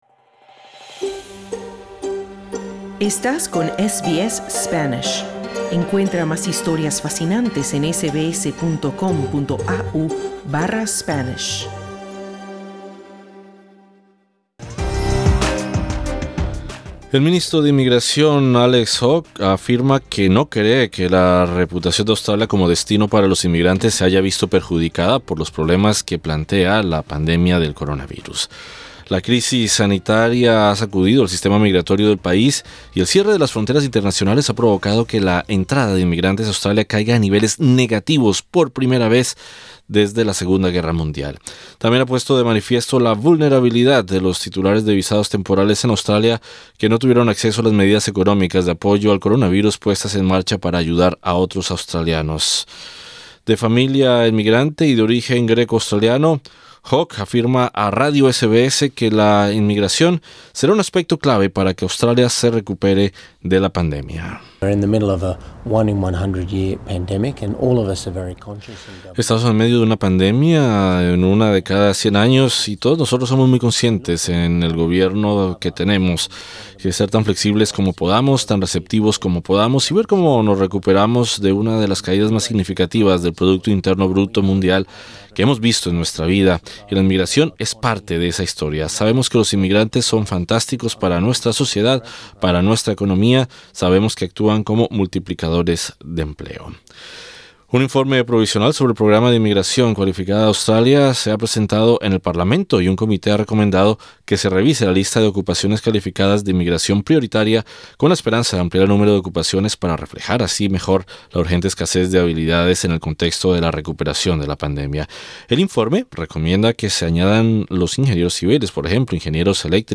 El ministro de Inmigración, Alex Hawke, afirma en entrevista con SBS News que no cree que la reputación de Australia como destino para los inmigrantes se haya visto perjudicada por los problemas que plantea la pandemia de coronavirus.